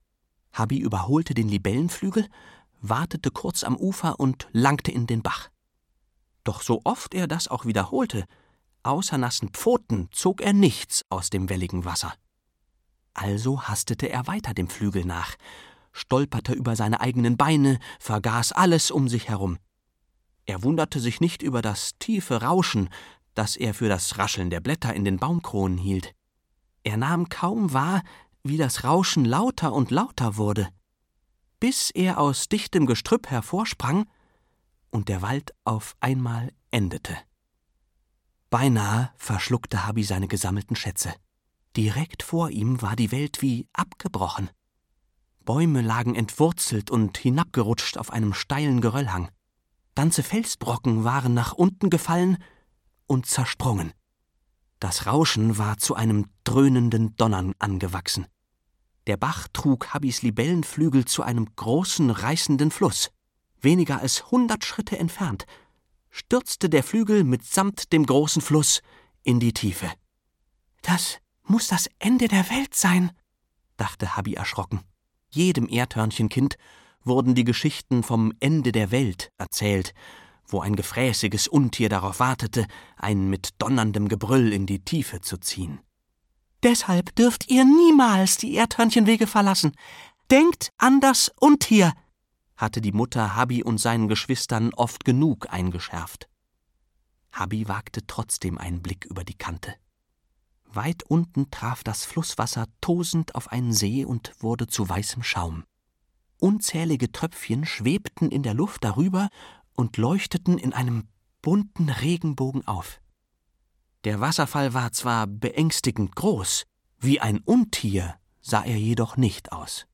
Ein Freund wie kein anderer - Oliver Scherz - Hörbuch